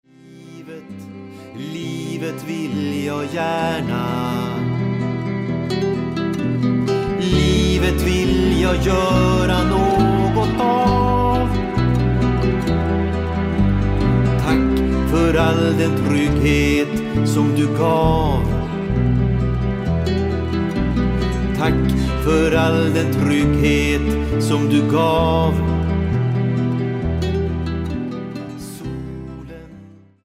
dragspel
oktav- och elgitarr, bas och kör
synth och ljudeffekter
Text, sång, musik och album: